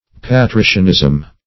Patricianism \Pa*tri"cian*ism\, n. The rank or character of patricians.